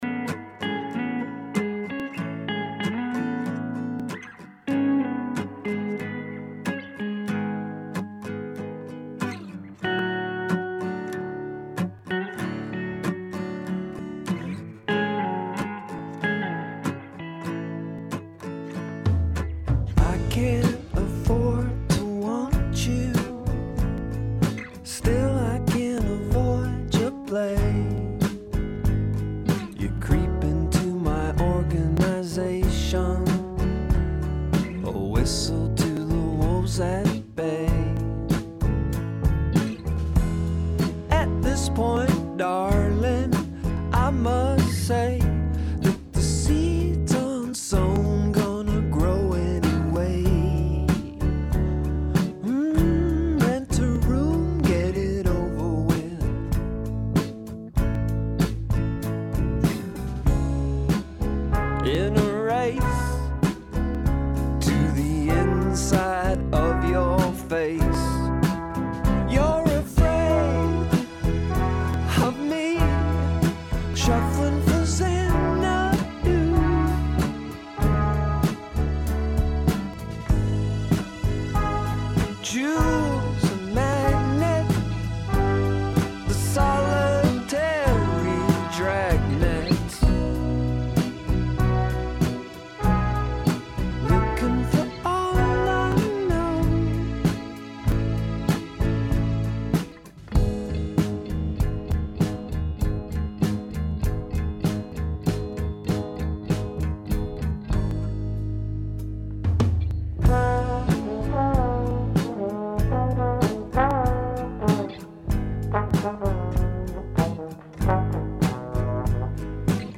rock progresibotik
Tuareg doinuetara egingo dugu bidaia